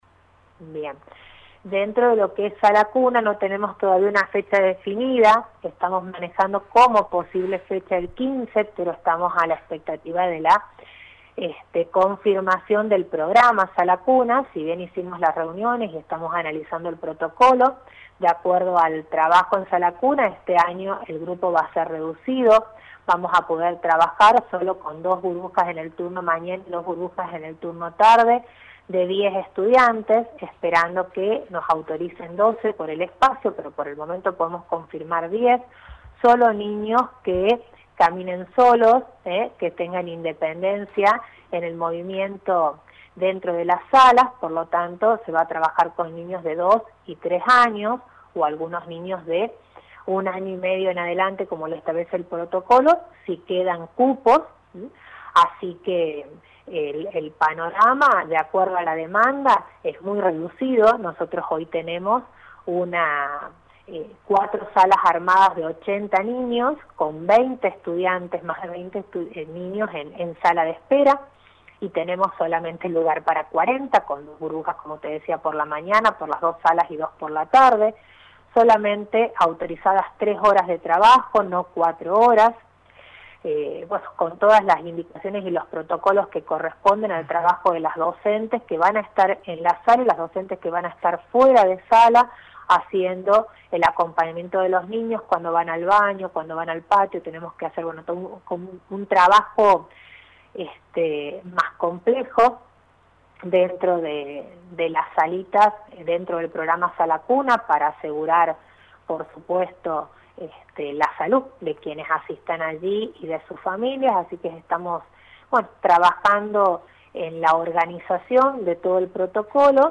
La secretaria de educación explicó que la Sala Cuna comenzará a funcionar desde los próximos días sólo para niñas y niños mayores a los dos años de edad.